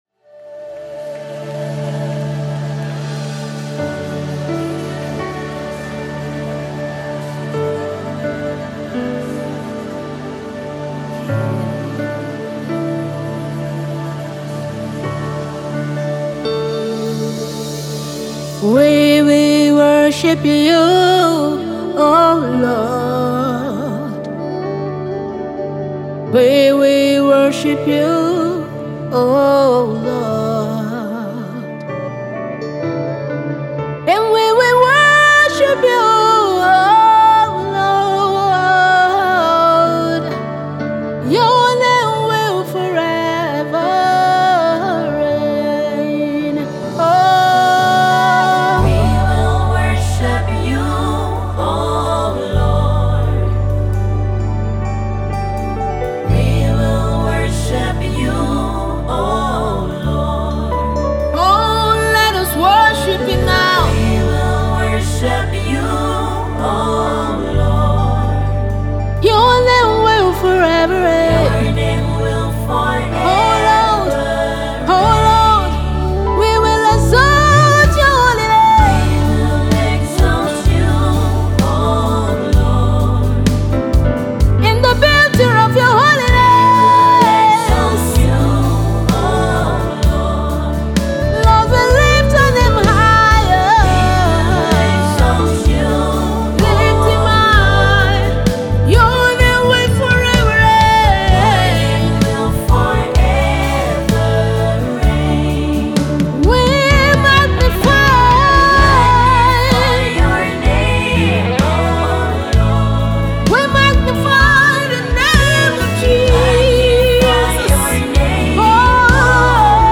Nigerian US-based gospel music minister and songwriter